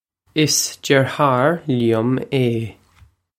Pronunciation for how to say
iss drih-hawr lyum ay.
This is an approximate phonetic pronunciation of the phrase.